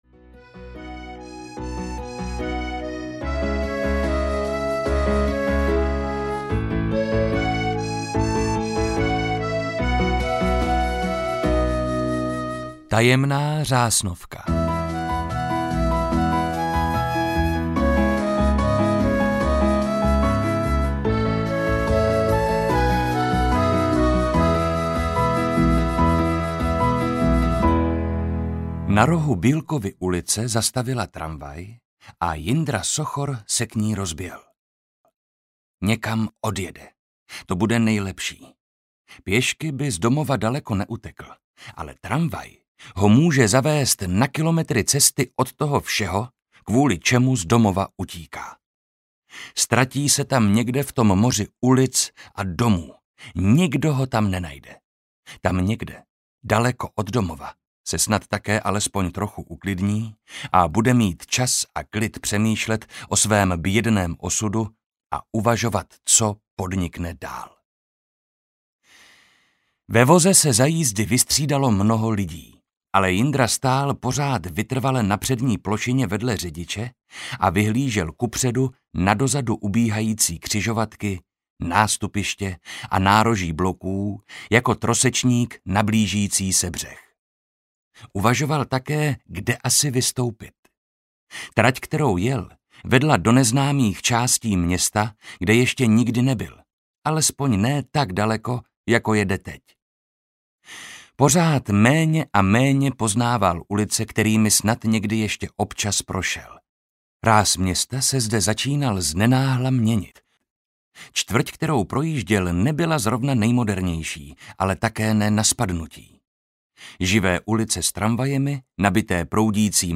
Tajemná Řásnovka audiokniha
Ukázka z knihy
• InterpretMarek Holý